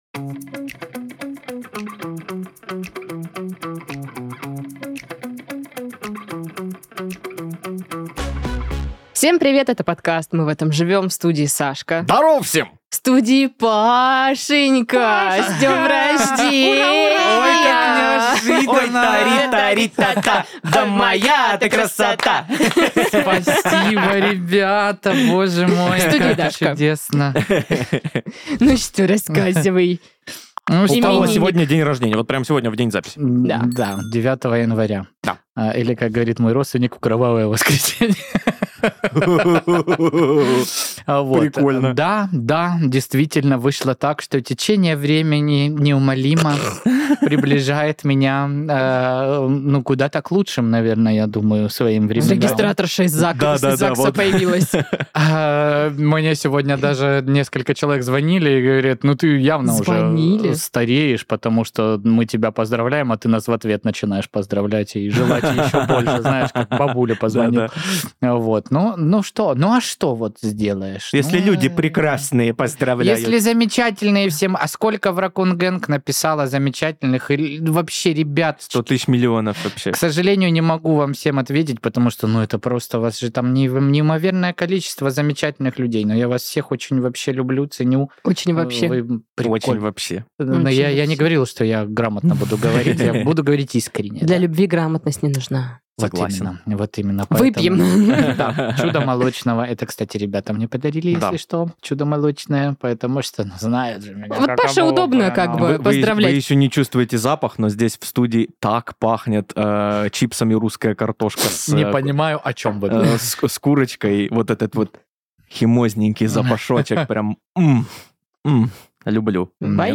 собираются в студии, чтобы обсудить забавные новости, смешные заголовки и повспоминать истории из жизни.